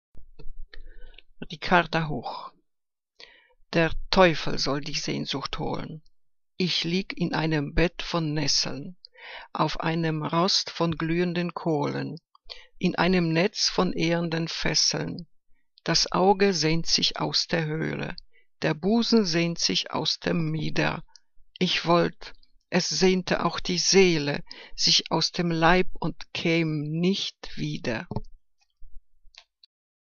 Liebeslyrik deutscher Dichter und Dichterinnen - gesprochen (Ricarda Huch)